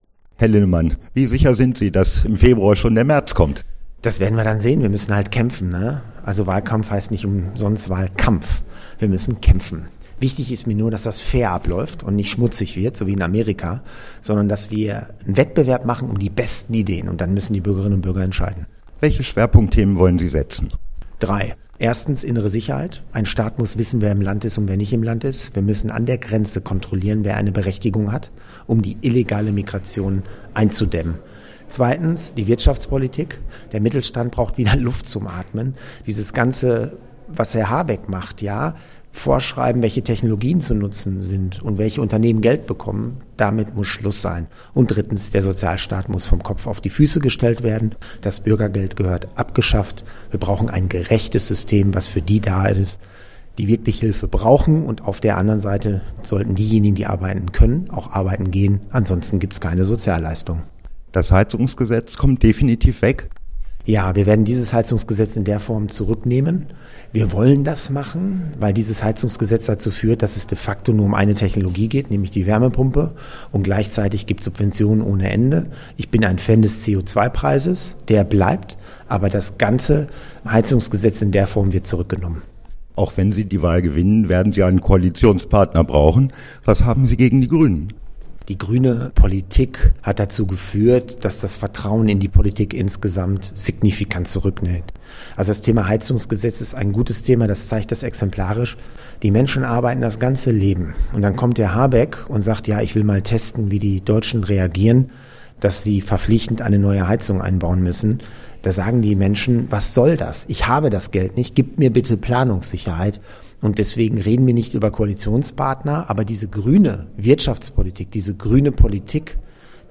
Weserbergland: CDU-Generalsekretär Carsten Linnemann bei Unternehmerfrühstück: CDU will Politikwechsel